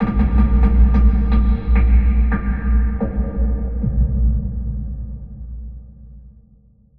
Processed Hits 13.wav